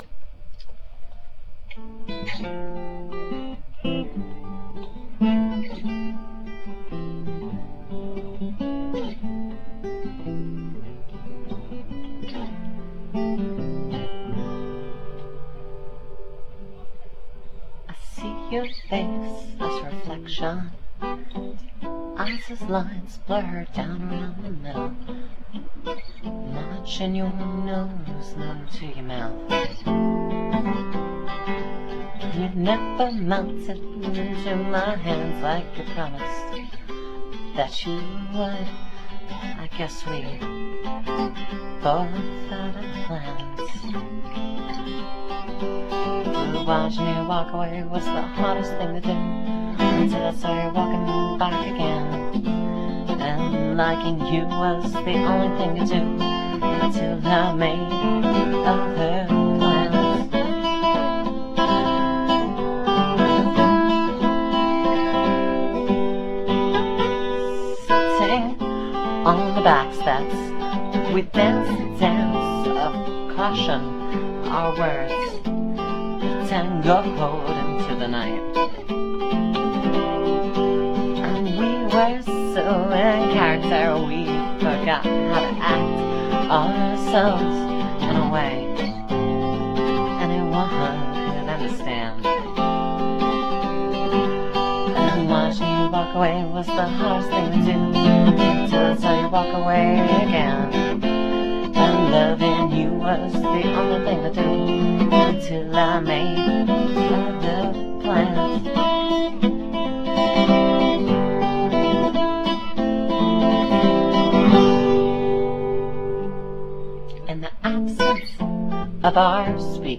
guitars and vocals